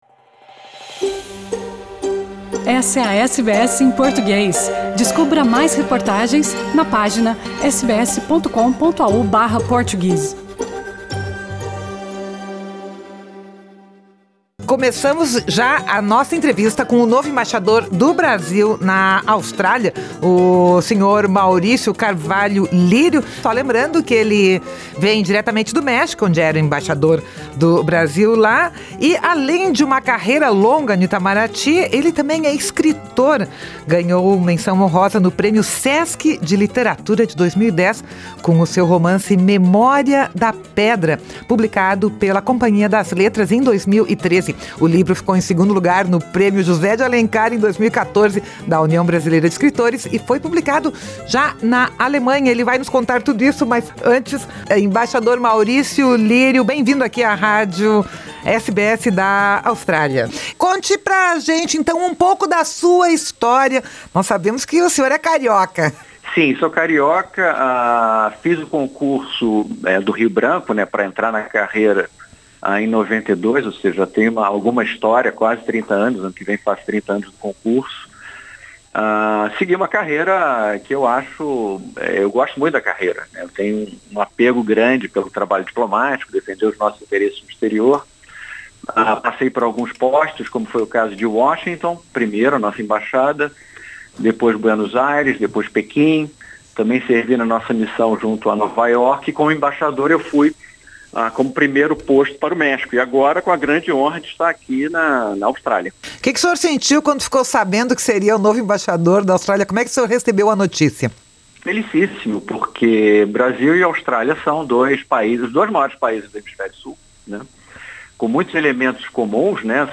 Em entrevista à SBS em Português, ele falou sobre os planos de sua gestão, que incluem trazer de volta à Austrália os brasileiros 'presos' no exterior devido à pandemia, implementar o visto Work and Holiday para brasileiros e expandir os laços entre Brasil e Austrália nas mais diversas áreas, com foco na agricultura.